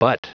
Prononciation du mot butt en anglais (fichier audio)
Prononciation du mot : butt